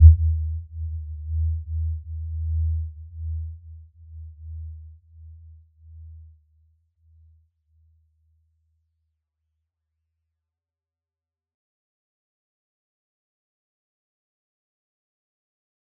Warm-Bounce-E2-f.wav